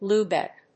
/ˈluˌbɛk(米国英語), ˈlu:ˌbek(英国英語)/